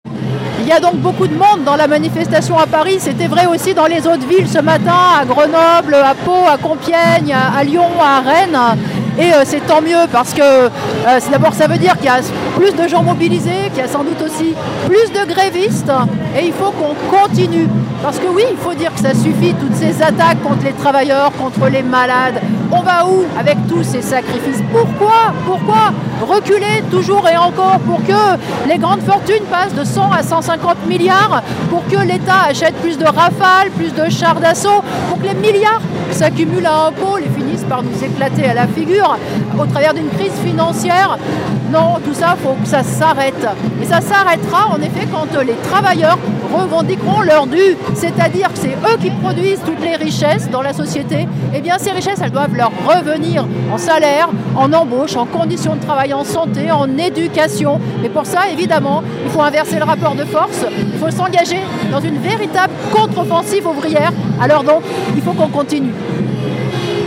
Dans la manifestation parisienne